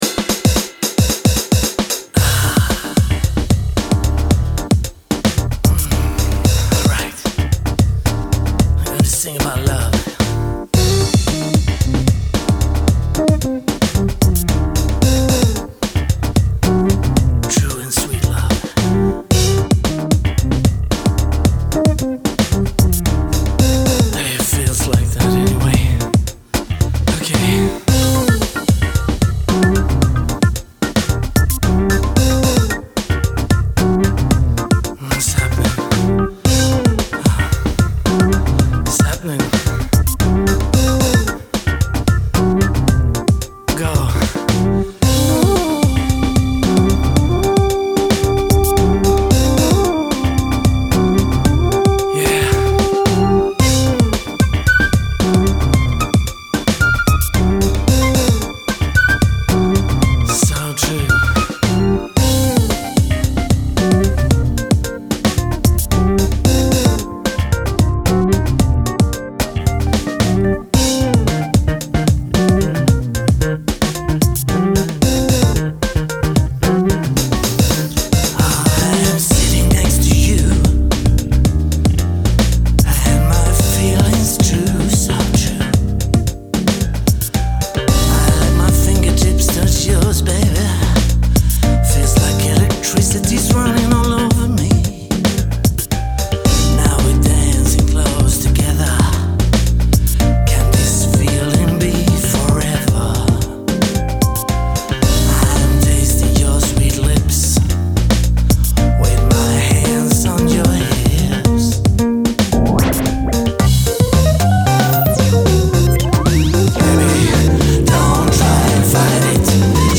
Disco/dance/R&B/Soul